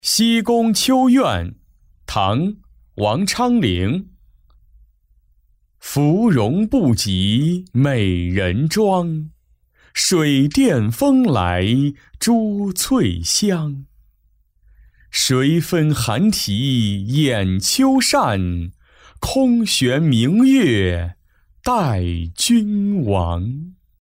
西宫秋怨-音频朗读